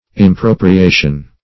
impropriation - definition of impropriation - synonyms, pronunciation, spelling from Free Dictionary
Impropriation \Im*pro`pri*a"tion\, n.